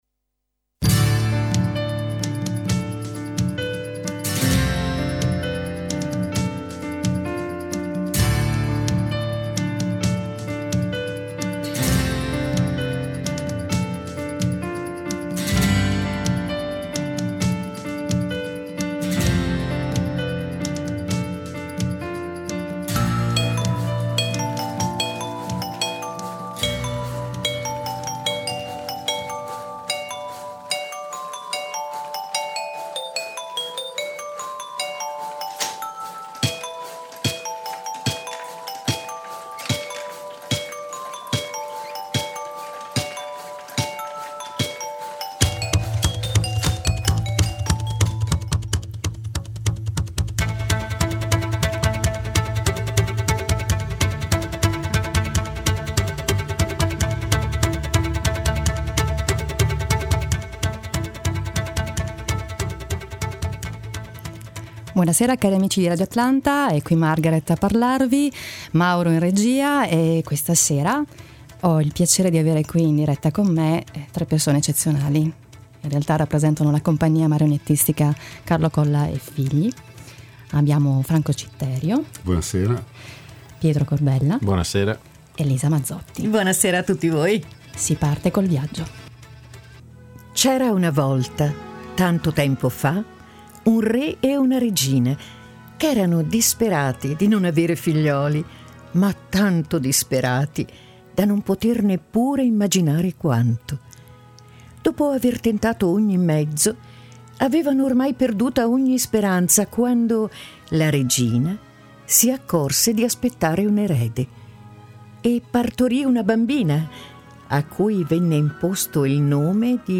Tre Ospiti in studio